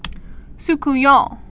(s-k-yäɴ)